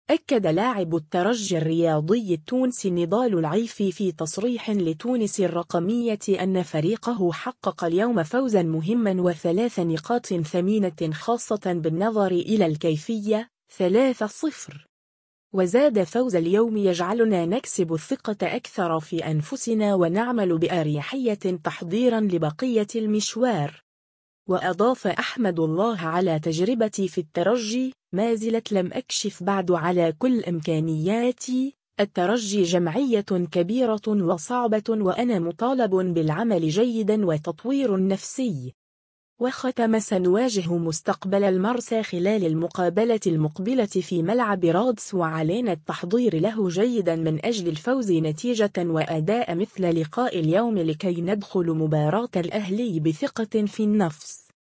في تصريح لتونس الرقمية